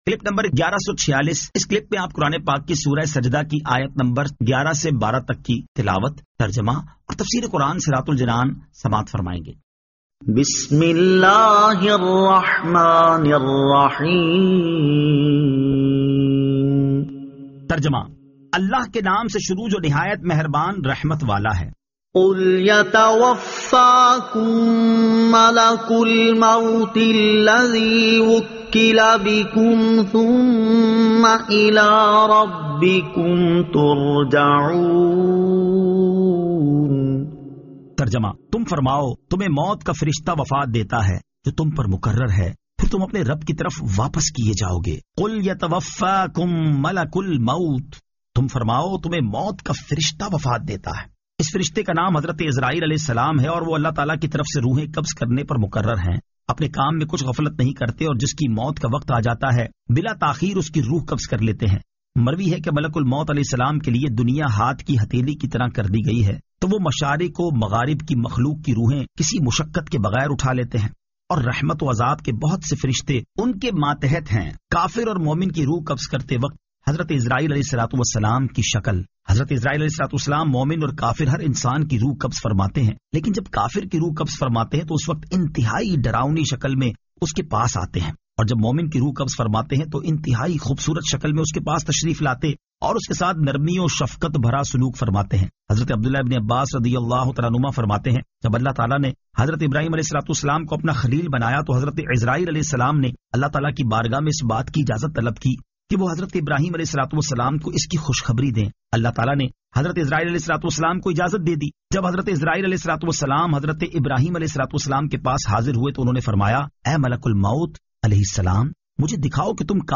Surah As-Sajda 11 To 12 Tilawat , Tarjama , Tafseer